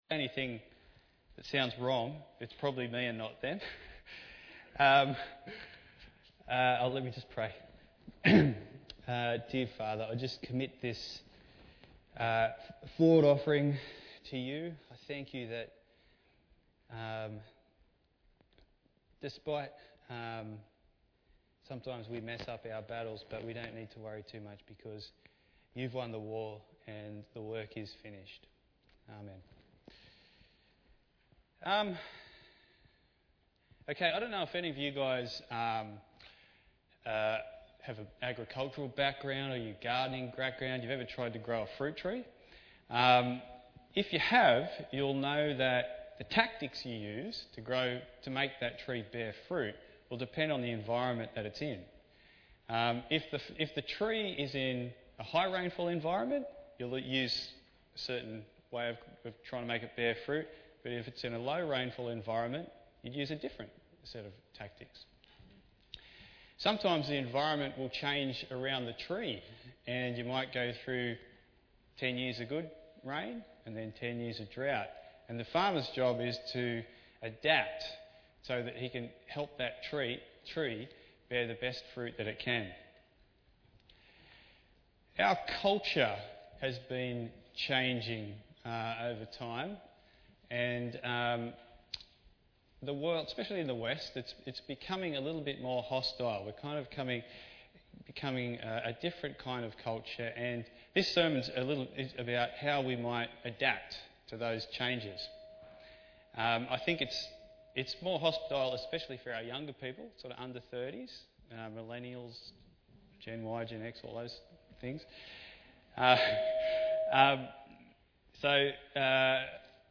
21/07/2019 Bearing Fruit in a Post-Church World Preacher